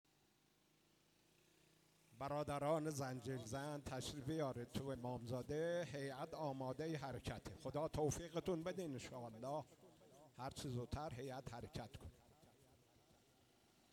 صدای حرکت هیأت پنج تن آل عبا قصردشت شیراز